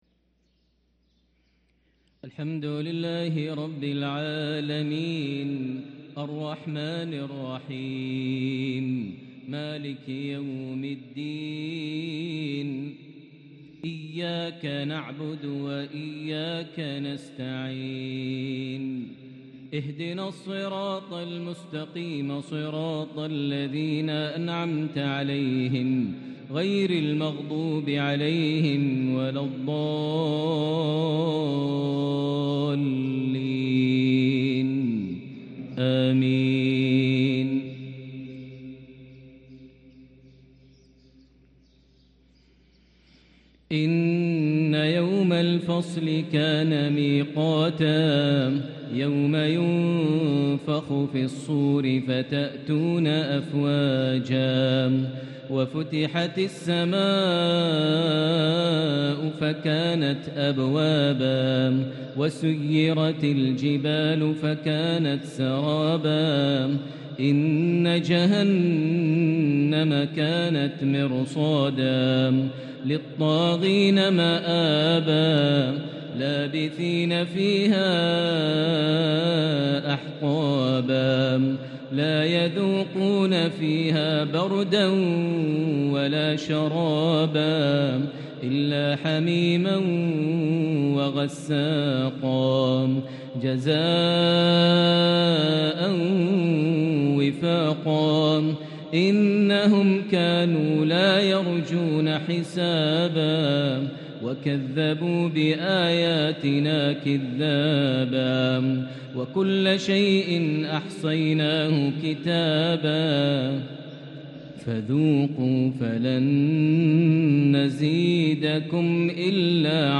صلاة المغرب ٨-٦-١٤٤٤هـ من سورة النبأ | Maghrib prayer from Surah an-Naba' 1-1-2023 > 1444 🕋 > الفروض - تلاوات الحرمين